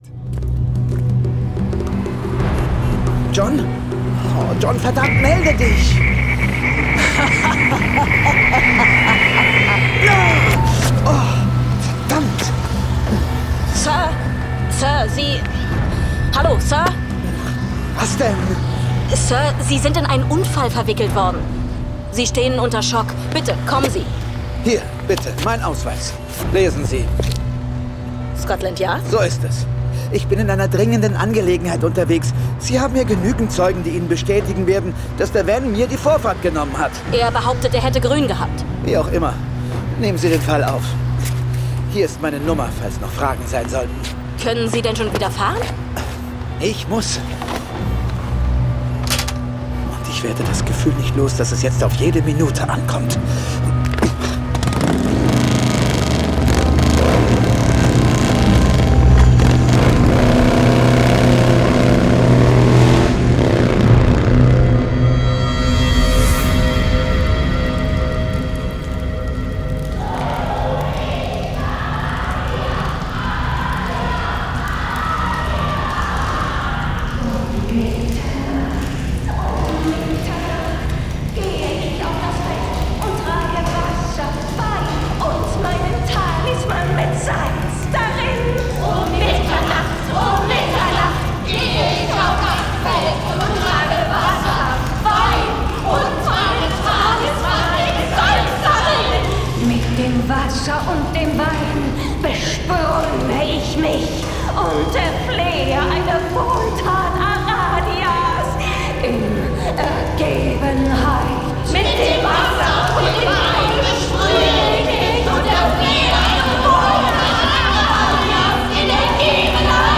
Jason Dark (Autor) Dietmar Wunder , diverse (Sprecher) Audio-CD 2025 | 1.